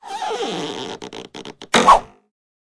На этой странице собраны реалистичные звуки выстрелов из рогатки разными снарядами: от камней до металлических шариков.
Звук выстрела рогатки